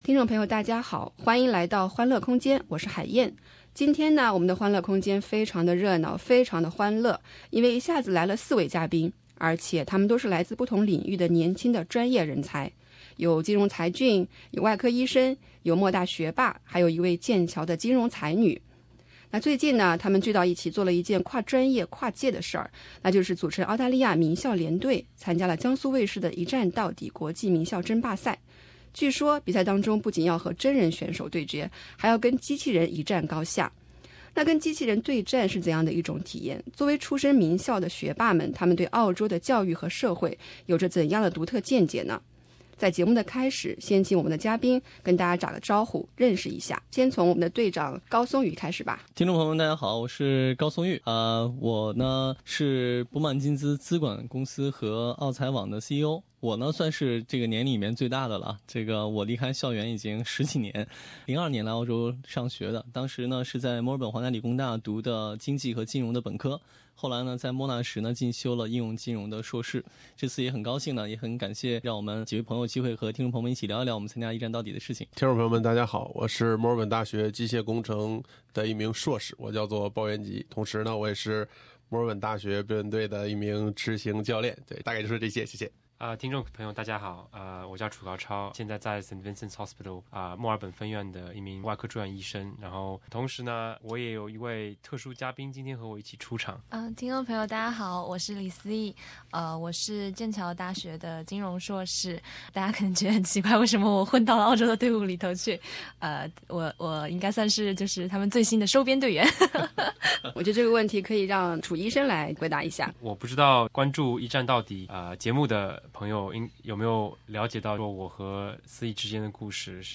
这个冬天，四位毕业于澳洲名牌院校的男生组队，飞赴中国某档热播益智综艺栏目的战场，感受了一把北半球的热火朝天。当他们作客欢乐空间，谈及更多的却是关于名校教育背后的思考、关于人生闯荡的体悟。